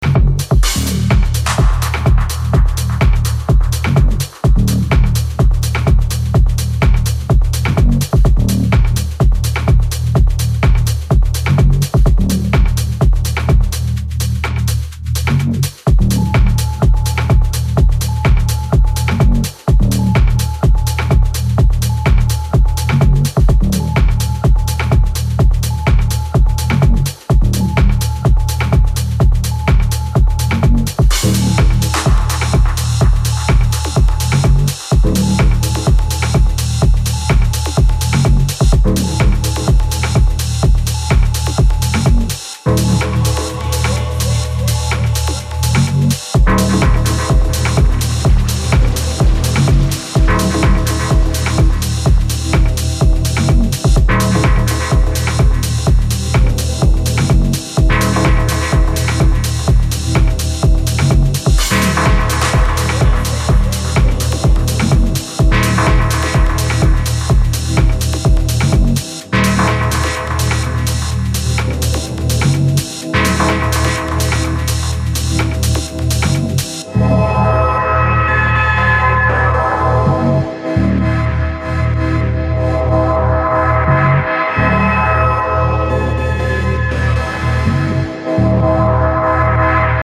featuring vocals